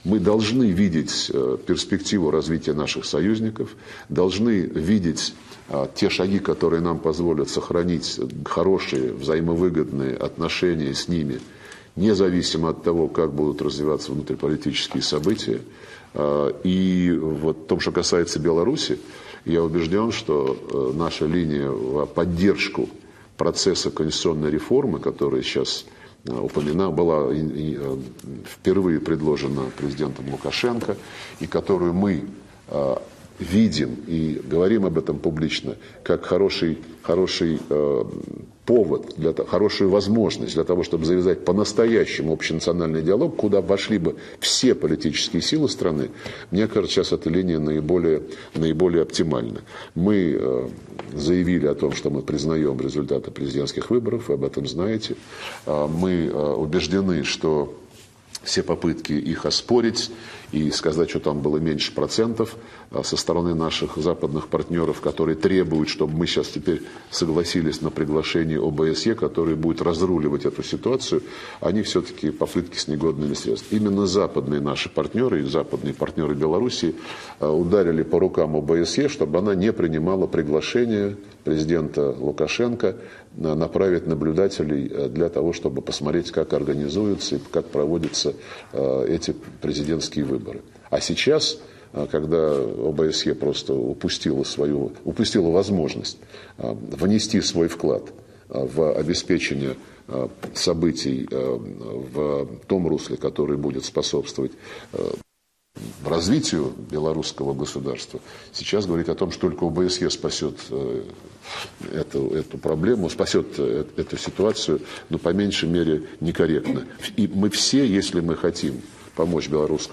Об этом в  интервью  российским радиостанциям Sputnik, «Комсомольская правда» и «Говорит Москва» заявил министр иностранных дел России Сергей Лавров.
Министр иностранных дел России Сергей Лавров